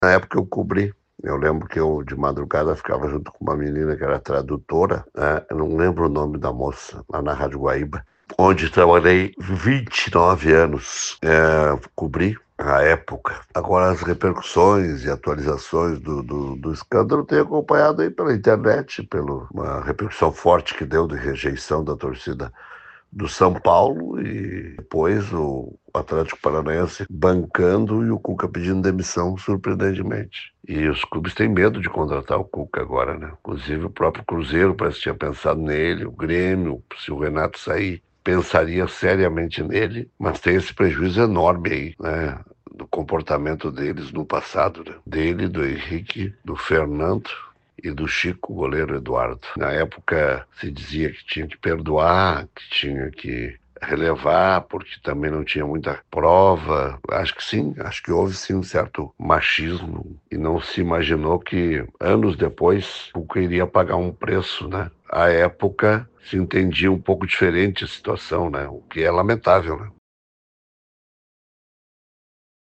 comentarista esportivo.